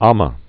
mə, ämä)